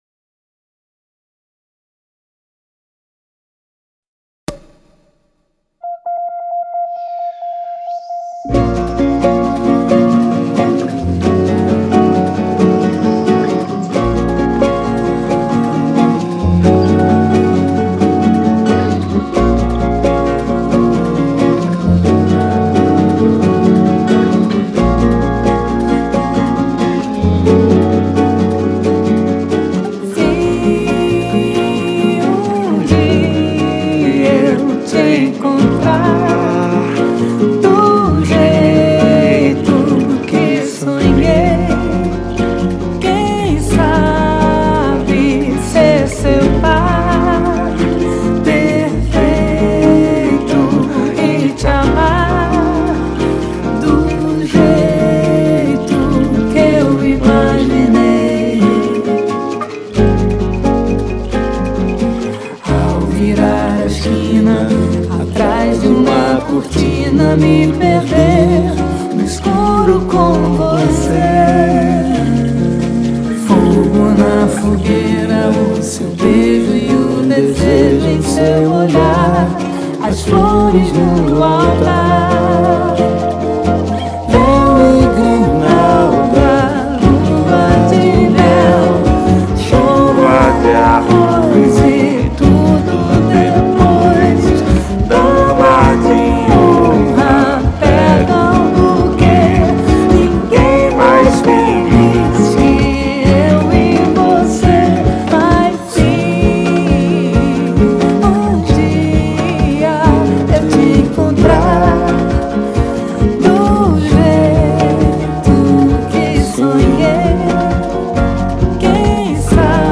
2025-02-23 01:14:06 Gênero: MPB Views